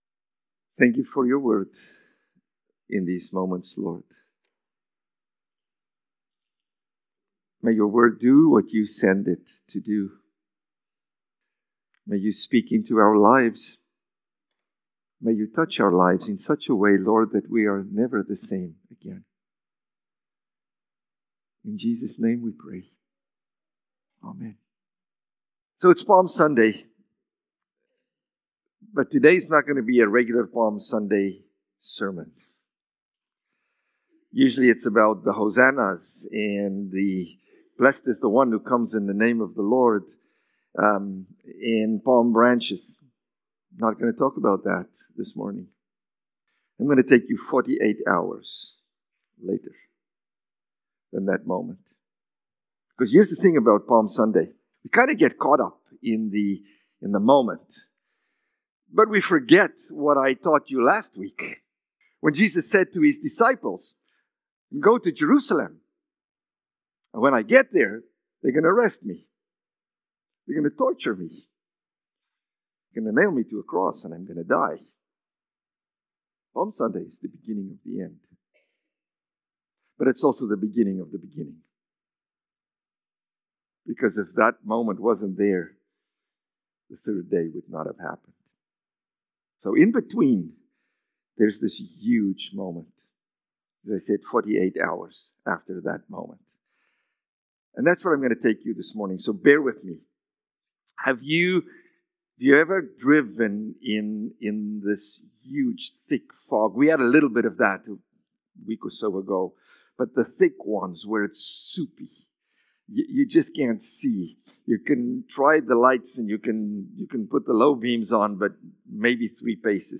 April-13-Sermon.mp3